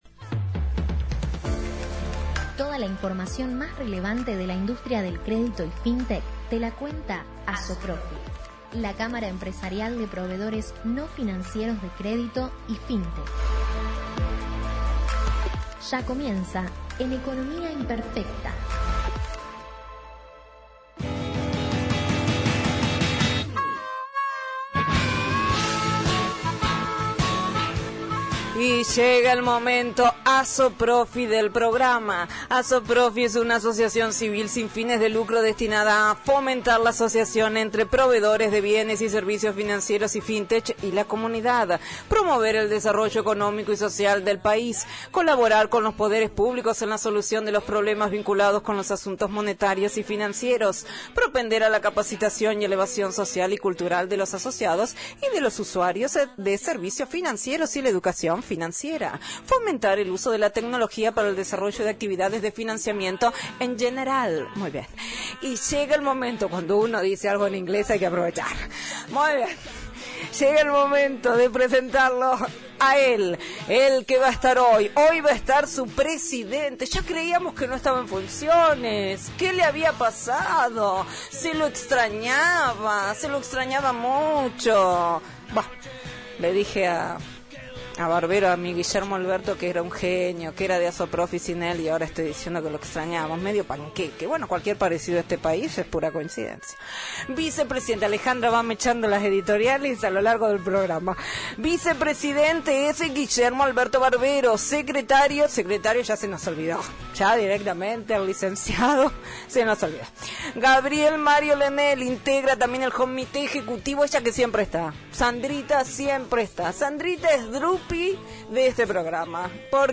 ASOPROFI – COLUMNA RADIAL – RADIO AM 1420 Viernes 22/10/21-““Fintech as a Service”